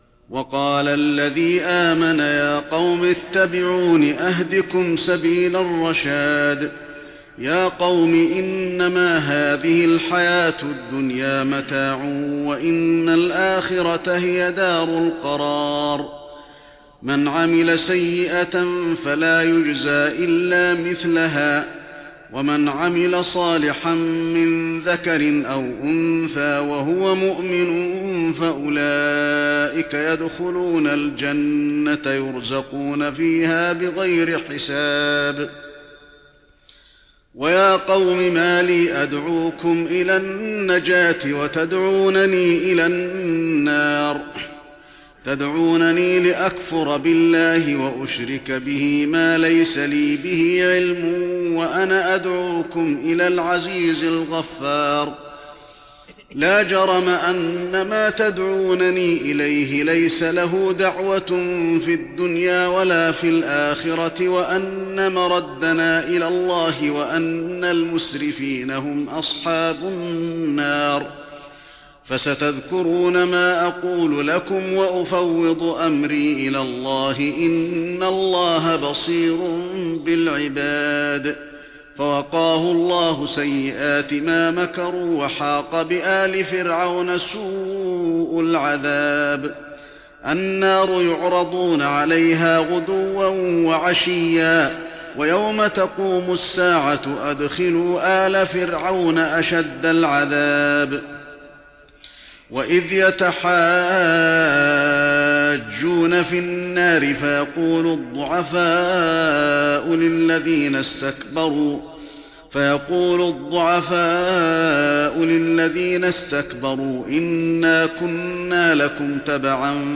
تراويح رمضان 1415هـ من سورة غافر (38-85) الى الشورى (1-12) Taraweeh Ramadan 1415H from Surah Ghaafir to Surah Ash-Shura > تراويح الحرم النبوي عام 1415 🕌 > التراويح - تلاوات الحرمين